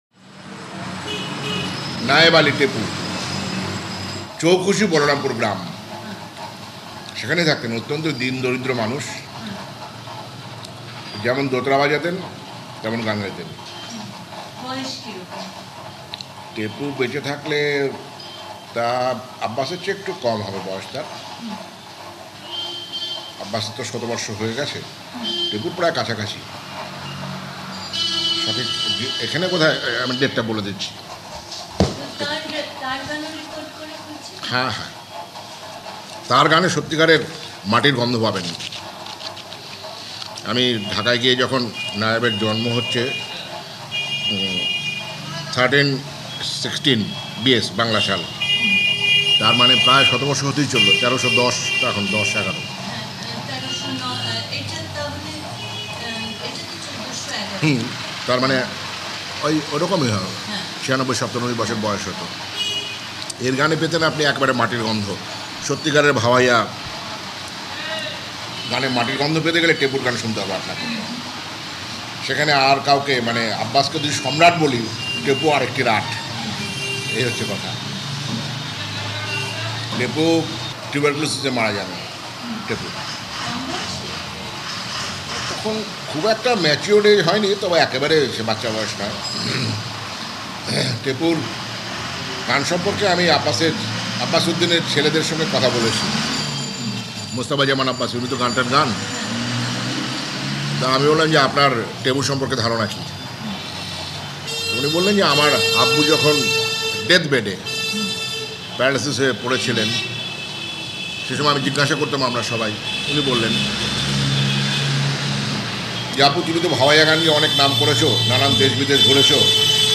Bhaoaiya
Bhaoaiya session.
The border town’s primary health centre where the session was taking place was transformed into a veritable studio.
It sounds well rehearsed and quite flawless—not something we’d usually expect from a field recording.
dotara
dhol
flute
mandira